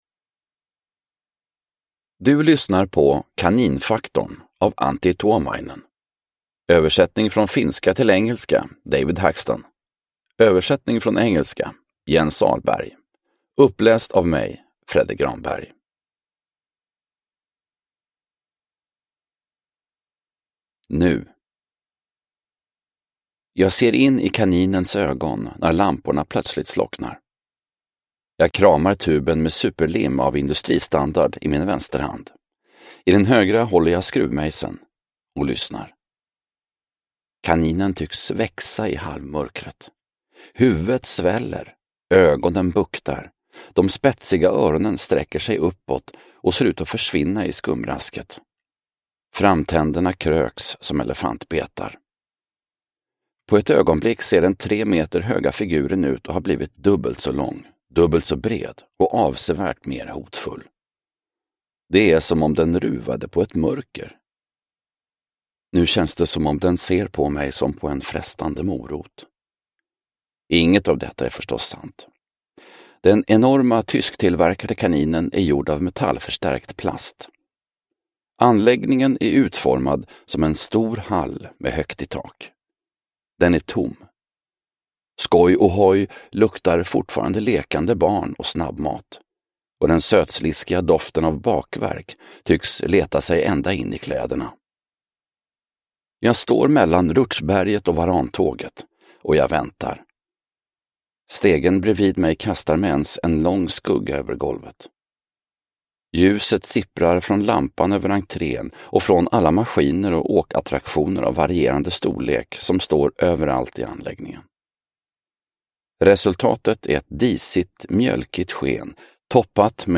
Kaninfaktorn – Ljudbok – Laddas ner